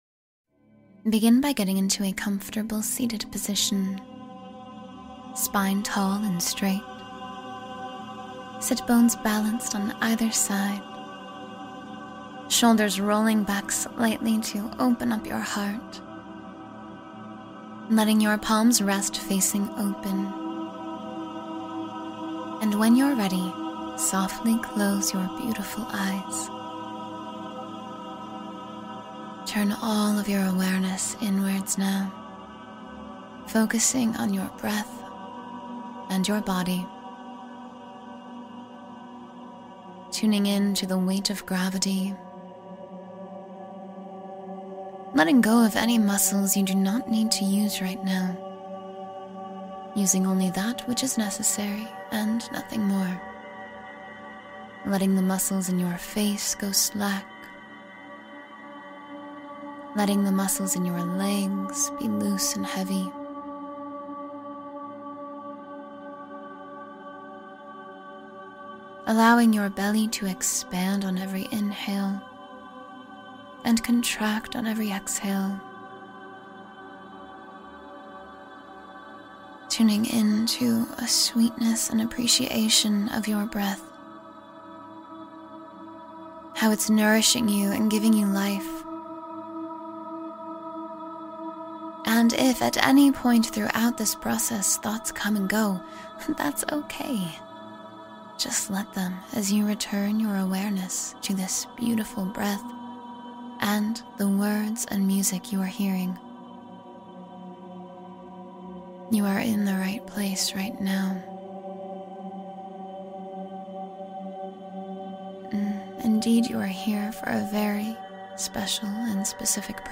10-Minute Manifestation Meditation for Alignment and Abundance